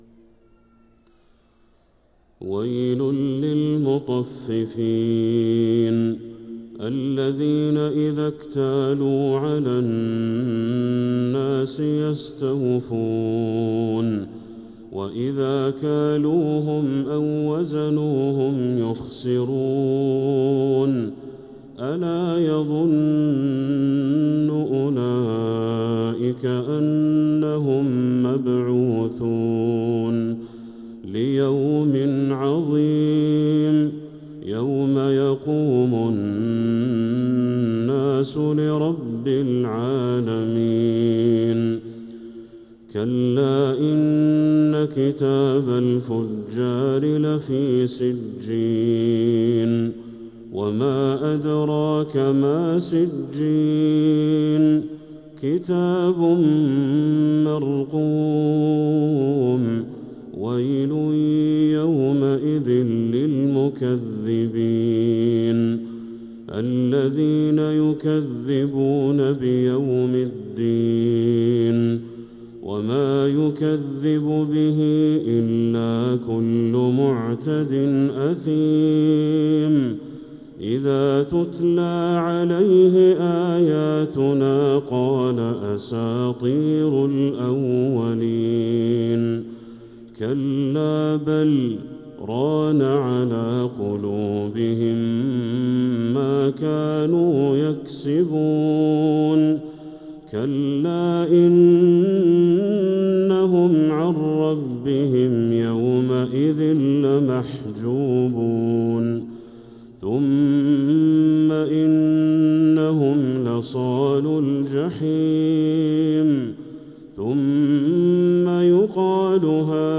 سورة المطففين كاملة | فجر رمضان 1446هـ > السور المكتملة للشيخ بدر التركي من الحرم المكي 🕋 > السور المكتملة 🕋 > المزيد - تلاوات الحرمين